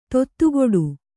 ♪ tottugoḍu